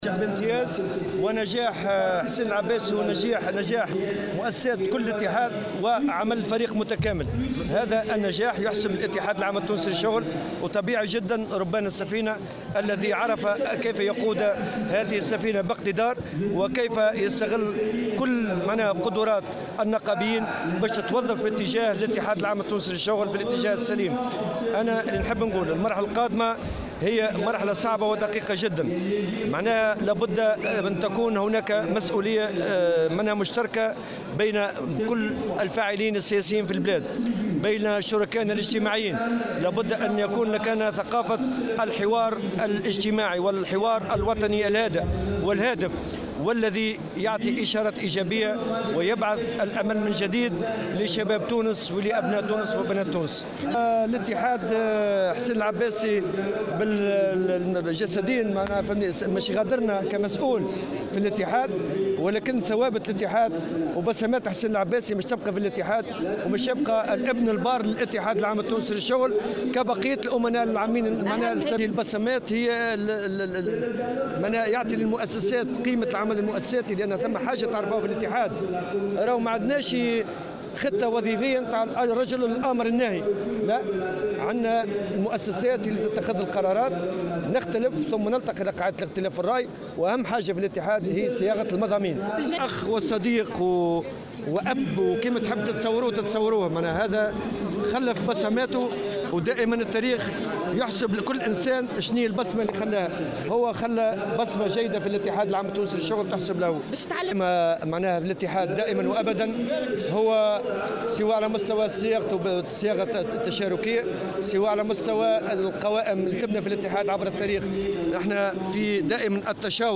وشدد بالقول في تصريحات صحفية على هامش مؤتمر الاتحاد المنعقد اليوم على ضرورة تحمل مسؤولية مشتركة بين الفاعلين السياسي والشركاء الاجتماعيين القائمة على ثقافة الحوار الاجتماعي الوطني الهادف والهادف، بحسب تعبيره.